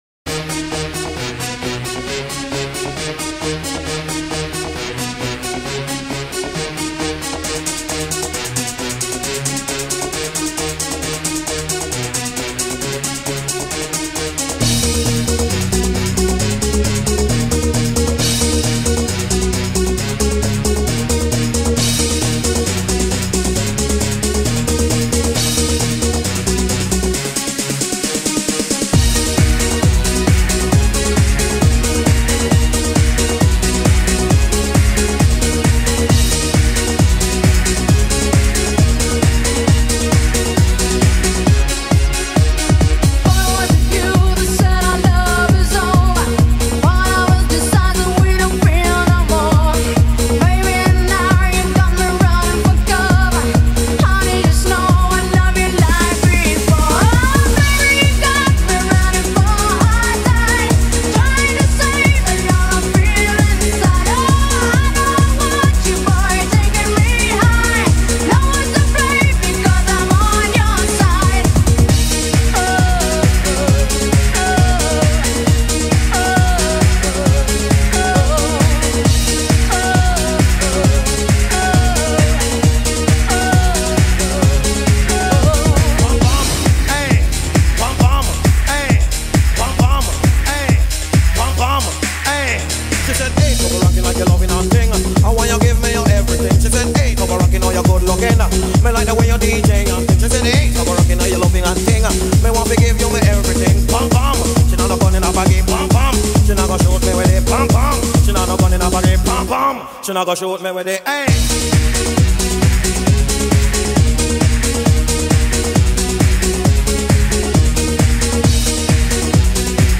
Eurodance_90_High_Energy_class_A.mp3